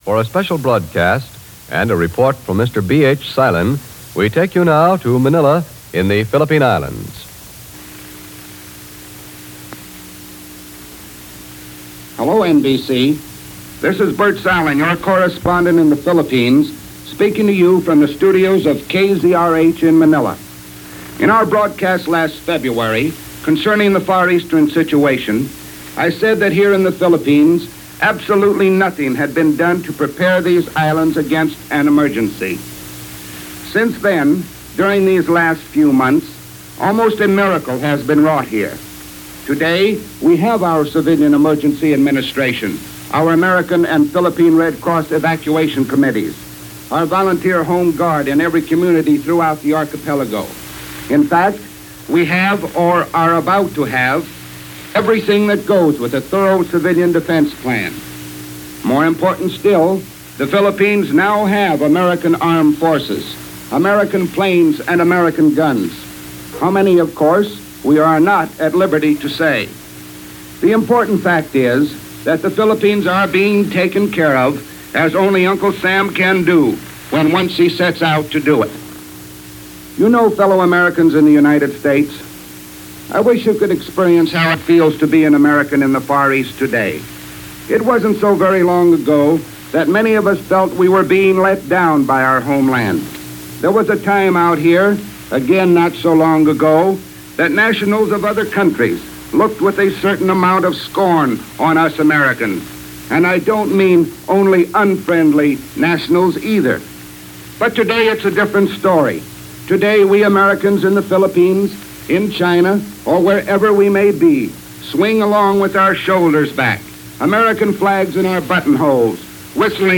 News for this day in 1941 – a view of the South Pacific as seen by American reporter